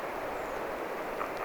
punakylkirastas äänteli saaressa, yksi ääni
Se äänteli monta kertaa.
Mutta niin hiljaisesti, että tämä oli äänistä selvin.
punakylkirastas_saaressa_yksi_aani_se_aanteli_kuitenkin_monta_kertaa.mp3